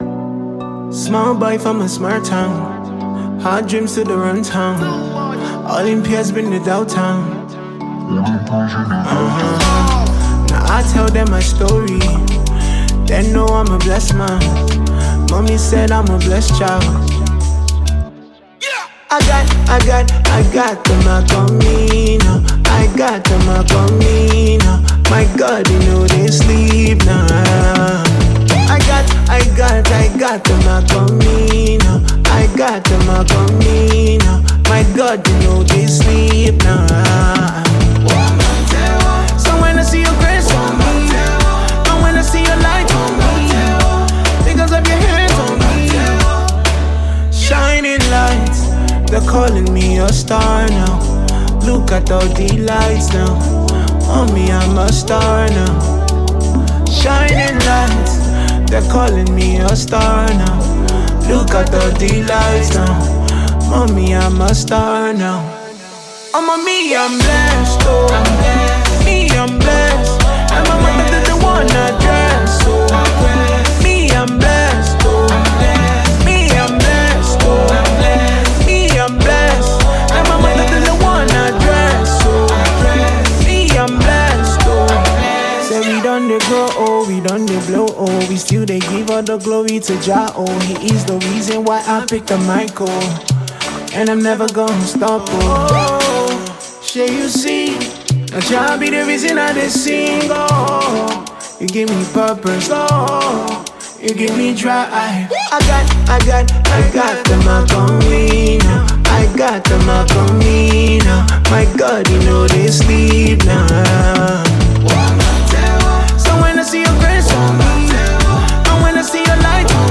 Mp3 Gospel Songs
Nigerian gospel songstress